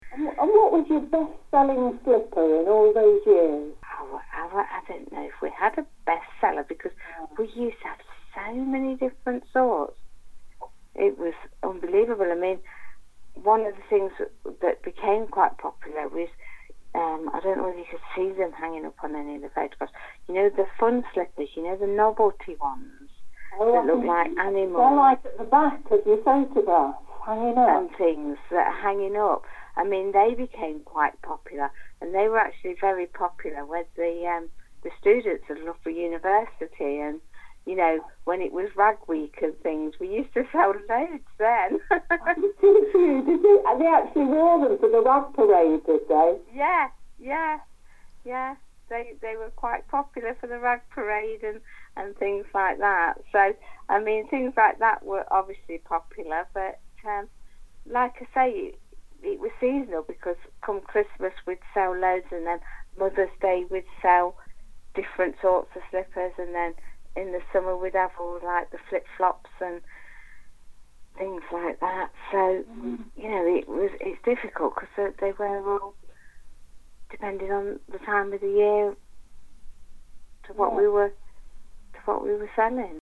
Oral History Interviews – Market
Listen below to audio clips taken from interviews conducted by our volunteers with the community reflecting on 800 years of Loughborough’s market.